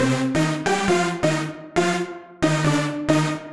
GA_SupiSyn136C-02.wav